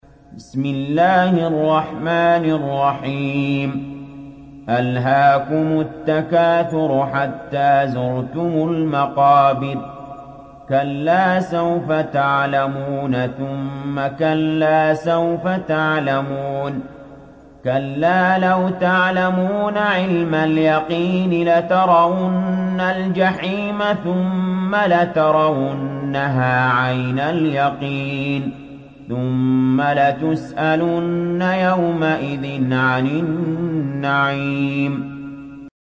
تحميل سورة التكاثر mp3 بصوت علي جابر برواية حفص عن عاصم, تحميل استماع القرآن الكريم على الجوال mp3 كاملا بروابط مباشرة وسريعة